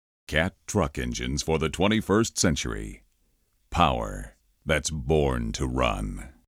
~ International Sales Conference Presentation ~